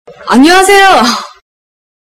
女生韩语你好音效_人物音效音效配乐_免费素材下载_提案神器